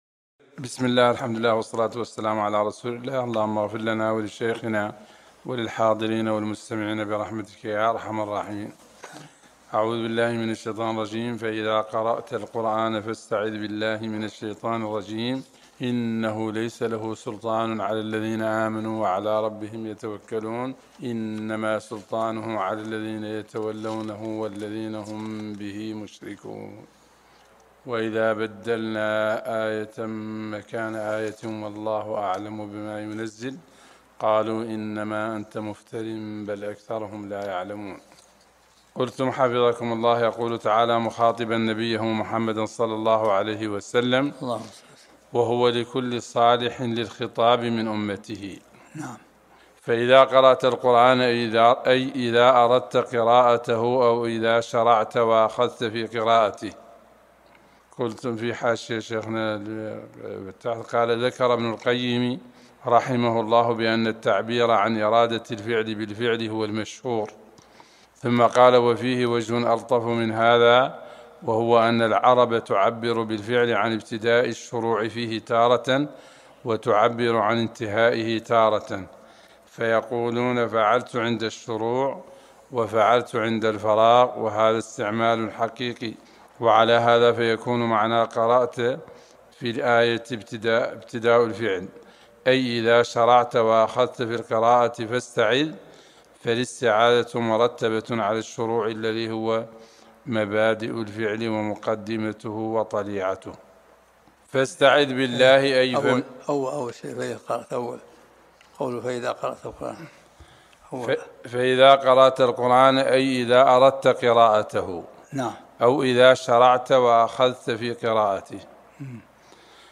الدرس الحادي عشر من سورة النحل